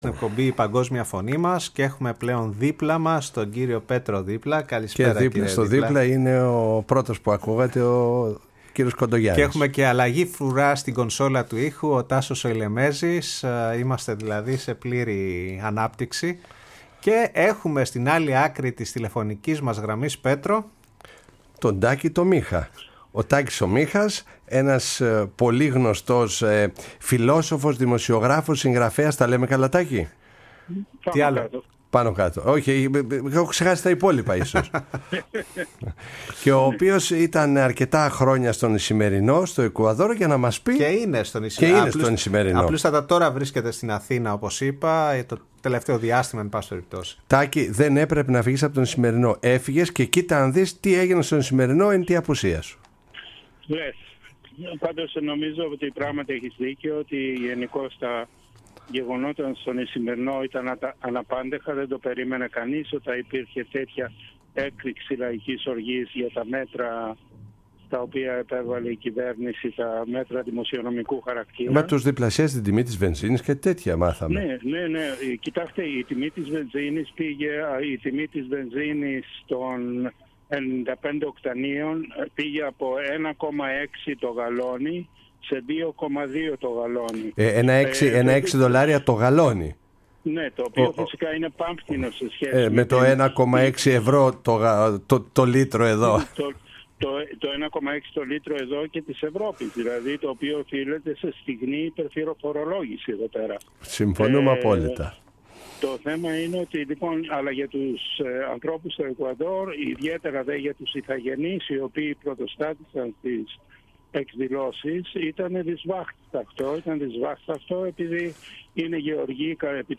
Στον Ισημερινό και στην εκεί ομογένεια ήταν αφιερωμένη η εκπομπή “Η Παγκόσμια Φωνή μας” στο ραδιόφωνο Φωνή της Ελλάδας.